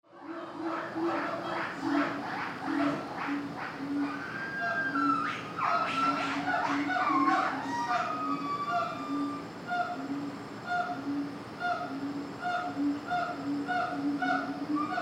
Zoo
Animal Sound Effects